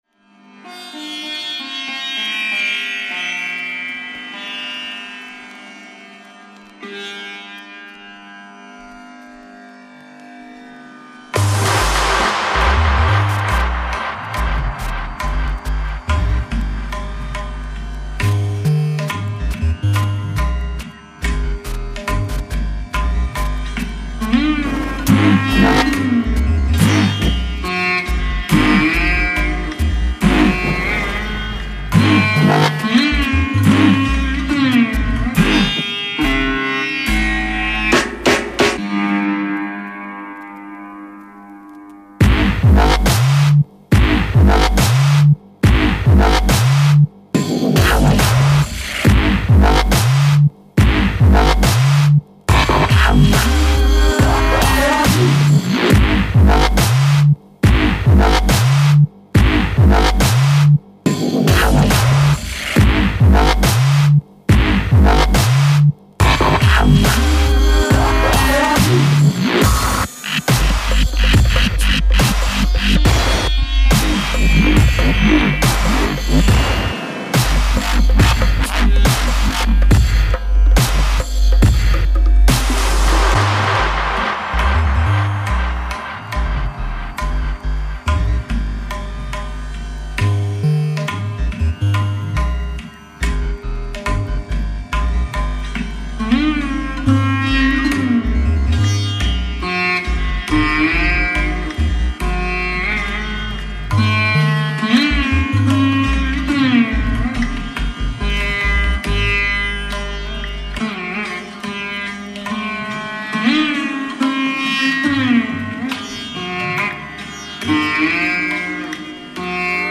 Category: Up Tempo